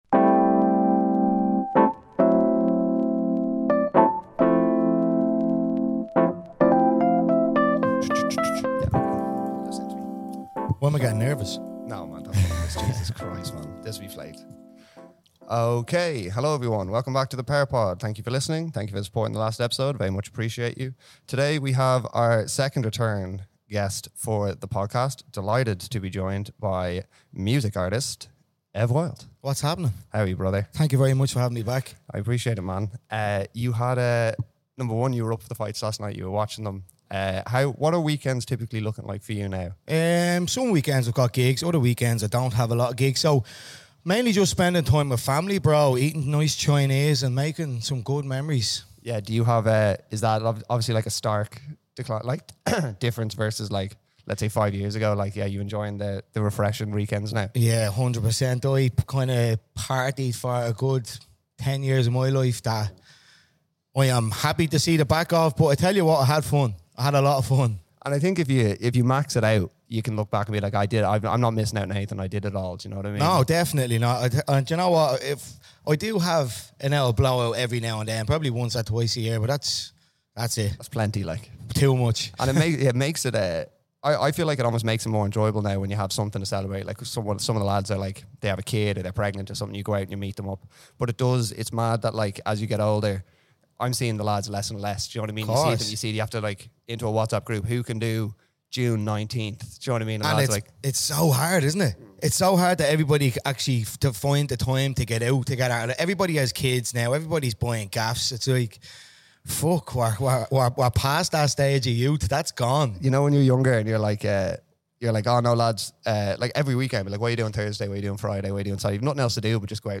This is by far one of the most honest and real conversations I have had so far.